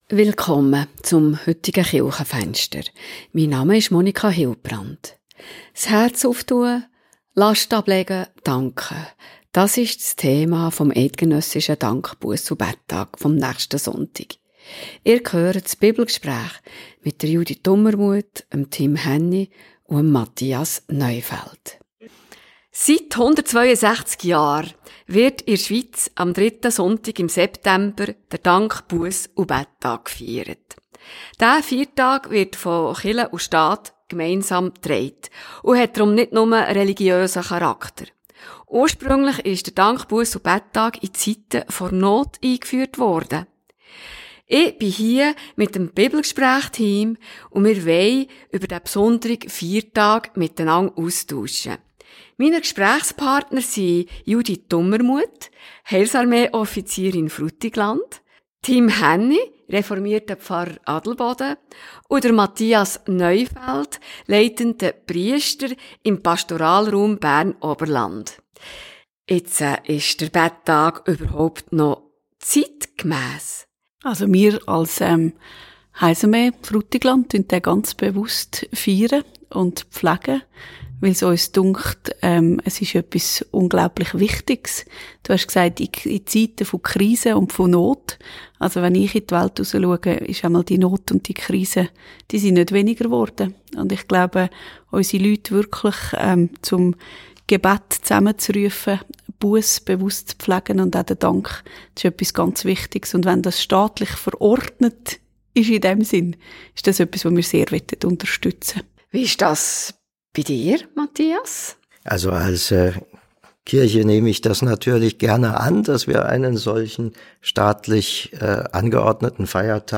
Beschreibung vor 6 Monaten Bibelgespräch vor dem Bettag Ist der Bettag noch zeitgemäss? Und was bedeutet Beten, Dankbarkeit und Busse heute?